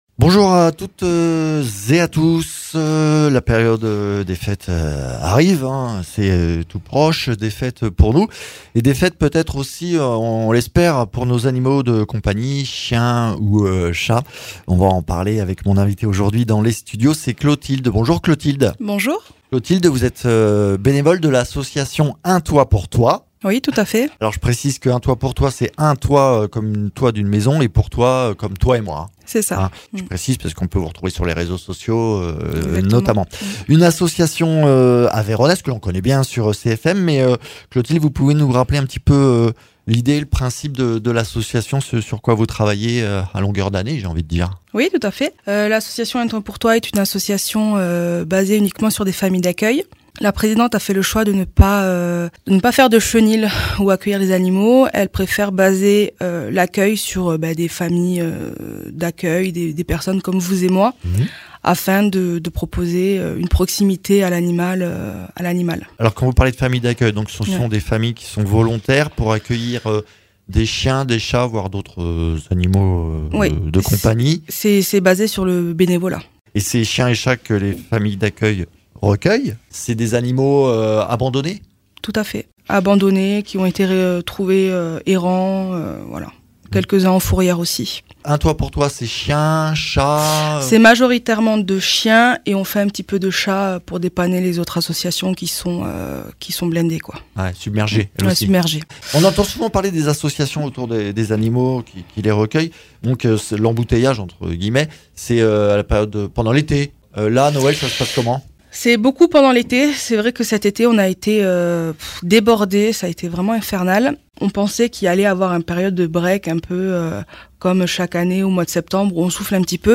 Le Mag Grand Rodez